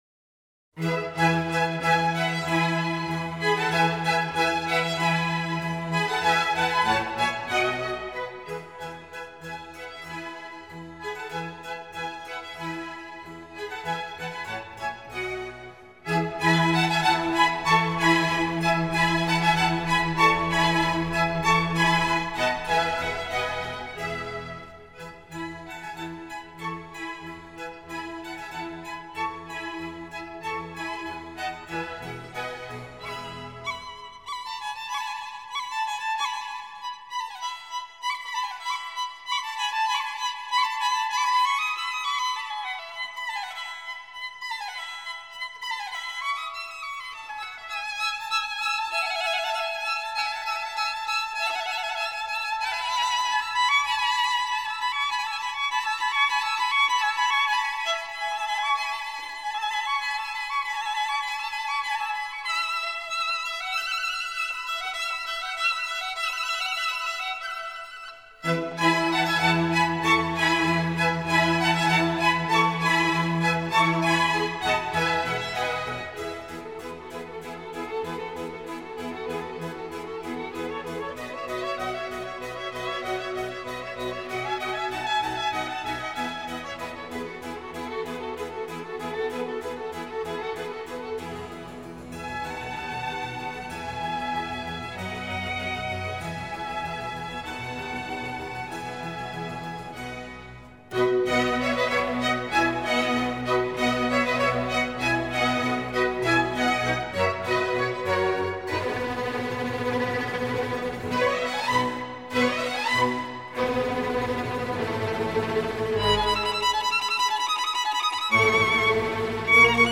موسیقی بی‌کلام "چهارفصل" بخش "بهار" موومان اول، آهنگساز: آنتونیو ویوالدی